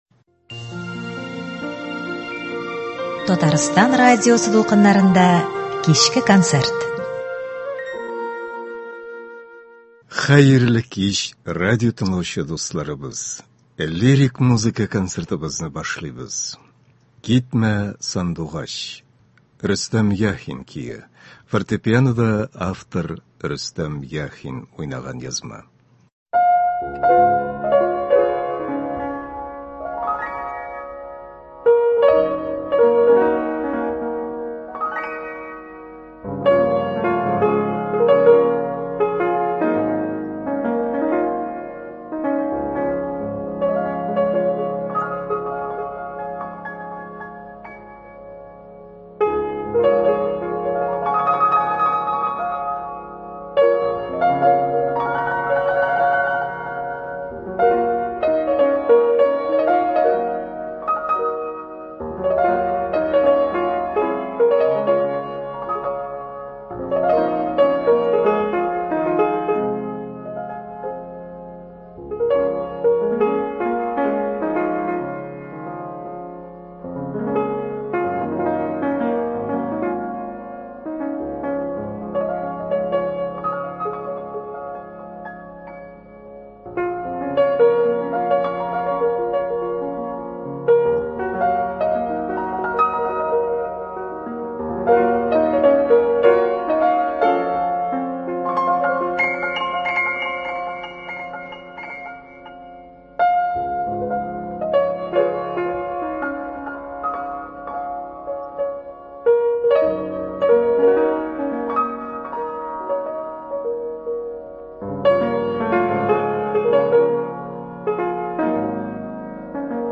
Лирик концерт.